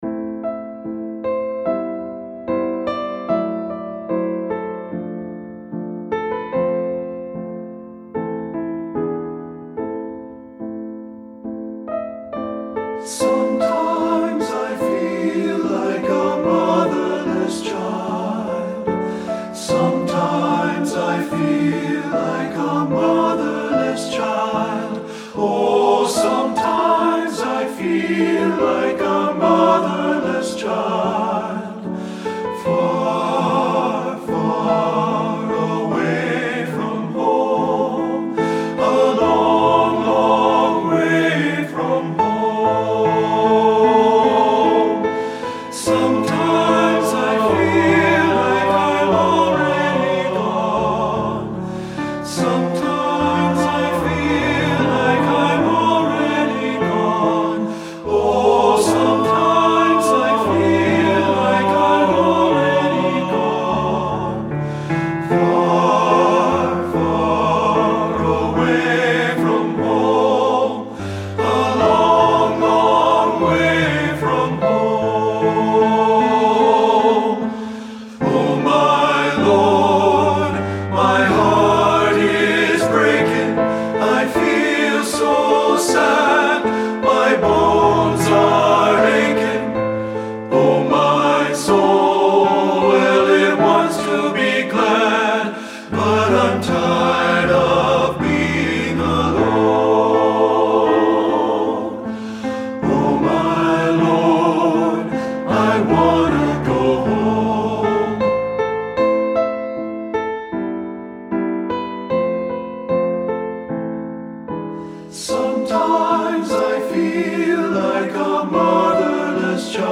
Voicing: T(T)B and Piano